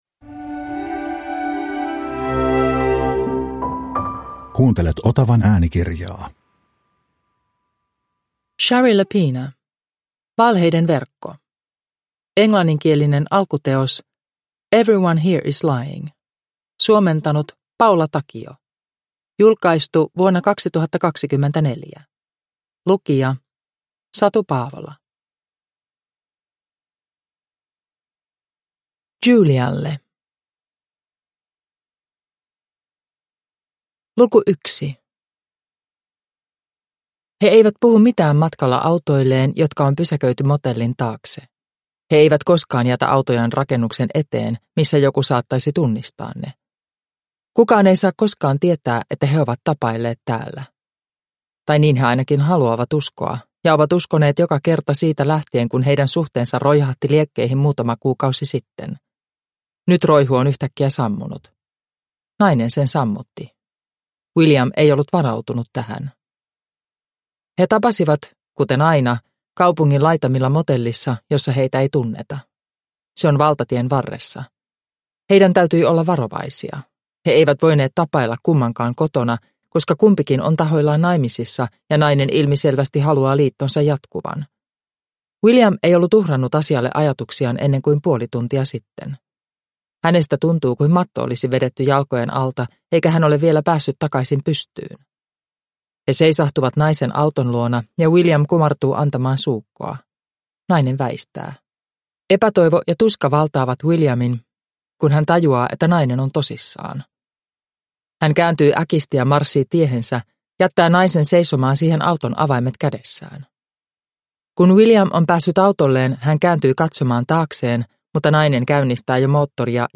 Valheiden verkko (ljudbok) av Shari Lapena